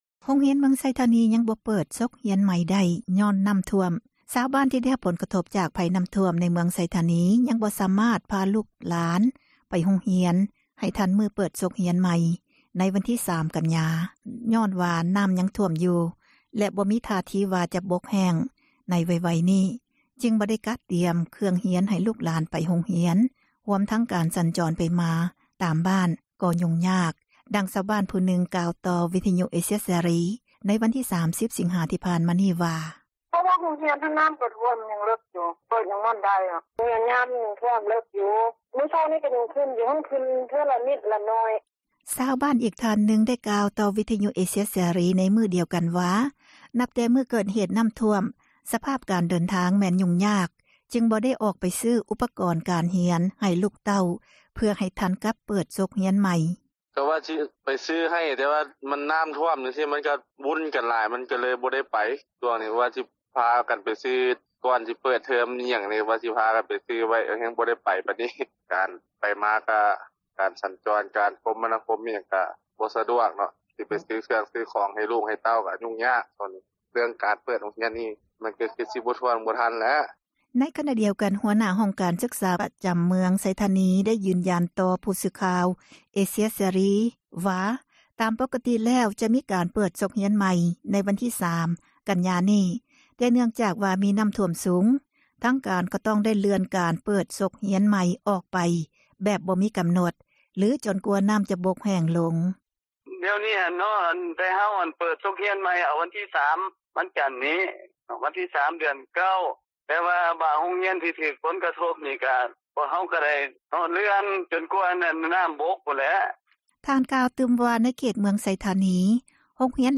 ຊາວບ້ານອີກທ່ານນຶ່ງ ໄດ້ກ່າວຕໍ່ວິທຍຸເອເຊັຽເສຣີ ໃນມື້ດຽວກັນວ່າ ນັບແຕ່ມື້ເກີດເຫຕນ້ຳຖ້ວມ ສະພາບການ ເດີນທາງແມ່ນ ຫຍຸ້ງຍາກ ຈຶ່ງບໍ່ໄດ້ອອກໄປຊື້ ອຸປກອນການຮຽນໃຫ້ລູກເຕົ້າ ເພື່ອໃຫ້ທັນກັບການ ເປີດສົກຮຽນໃໝ່: